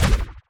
etfx_shoot_storm.wav